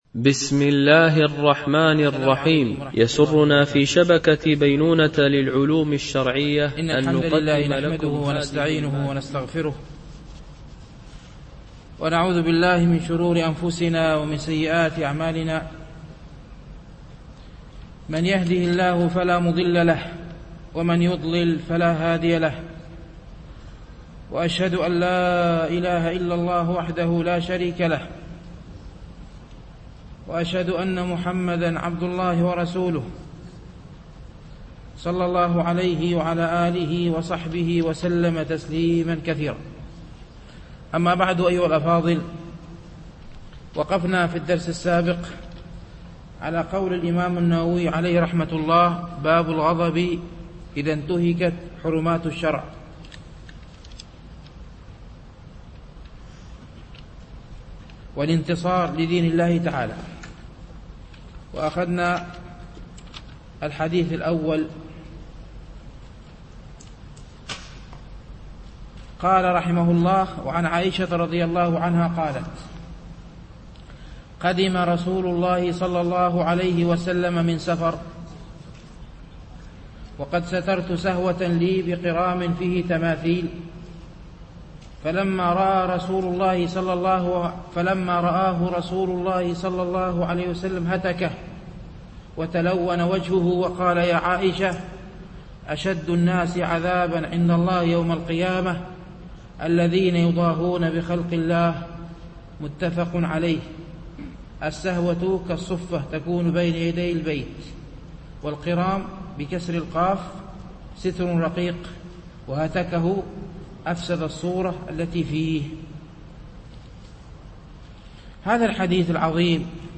شرح رياض الصالحين - الدرس السابع والسبعون بعد المئة